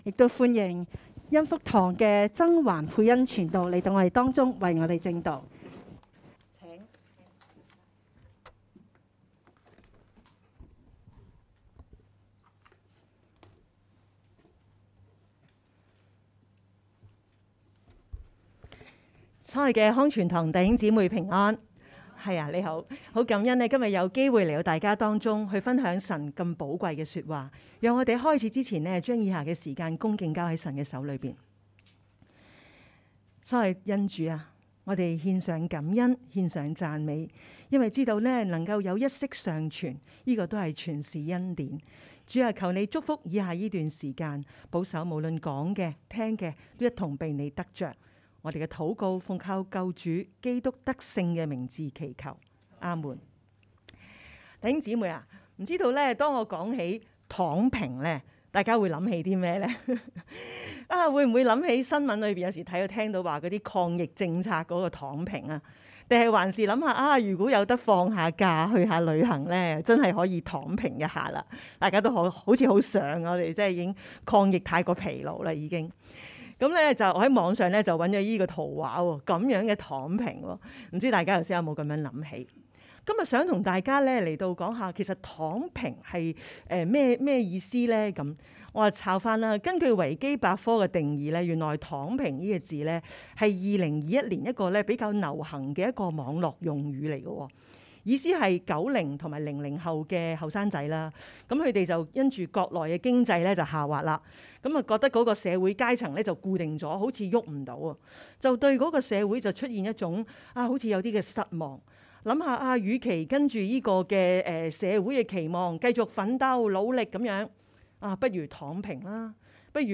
2022 年 8 月 20 日及 21 日崇拜